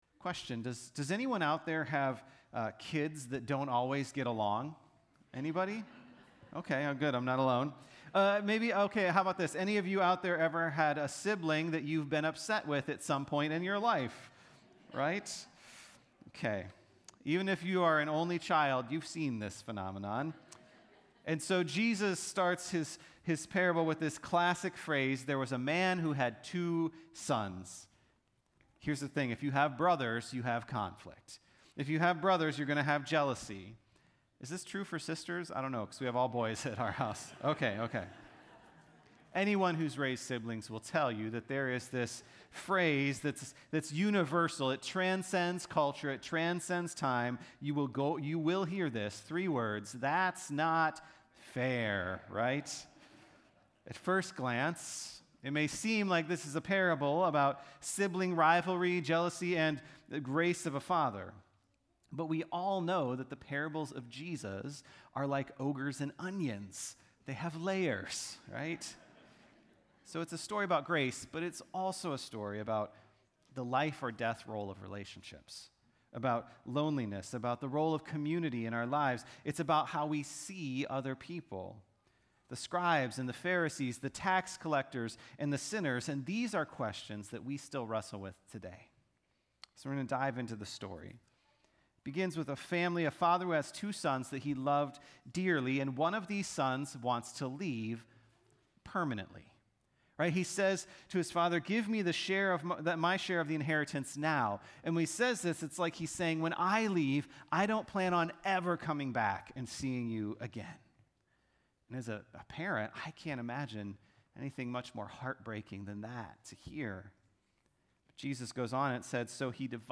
Sermons | Good Shepherd Lutheran Church